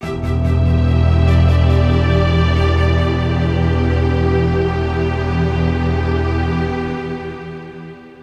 Ráfaga musical. Intriga.
intriga
melodía
Sonidos: Música